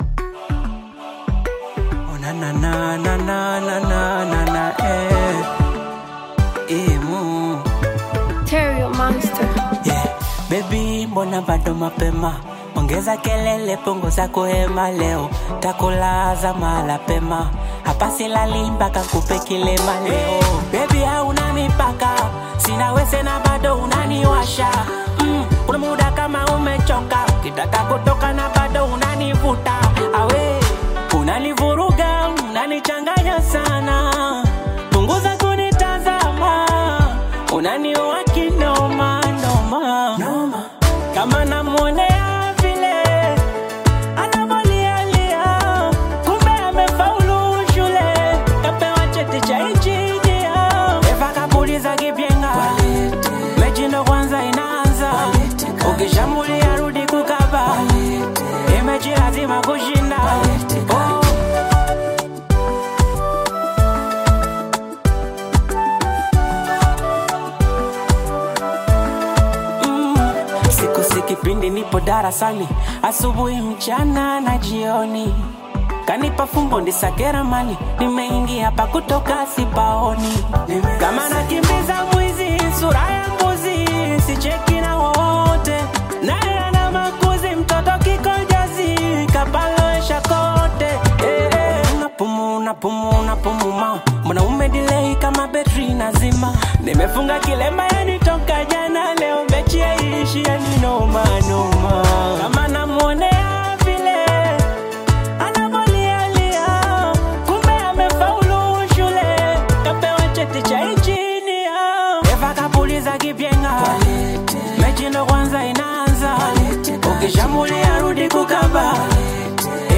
delivers a bold and confident message
blends catchy melodies with modern production